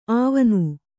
♪ āvanu